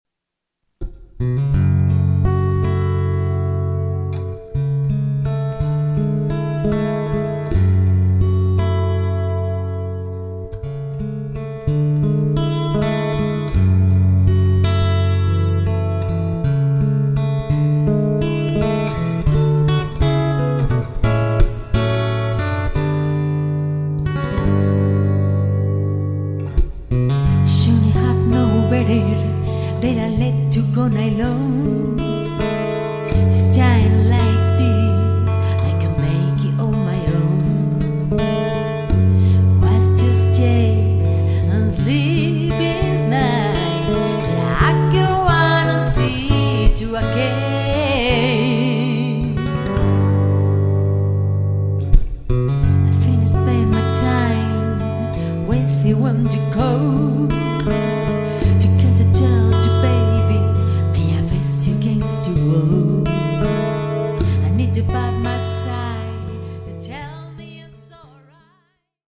Voz y Coros
Guitarras
Studio